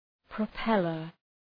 Προφορά
{prə’pelər}